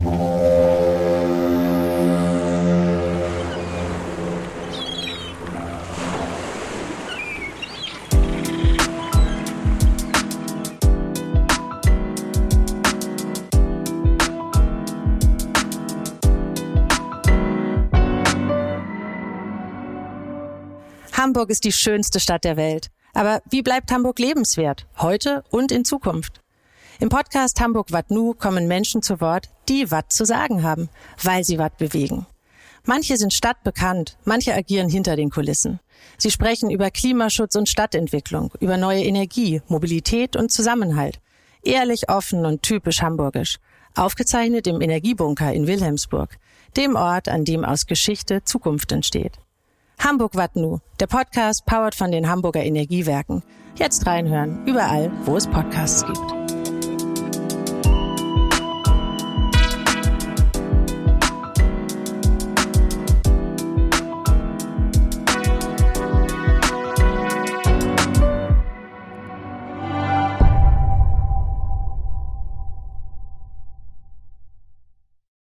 Aufgenommen im Café Vju im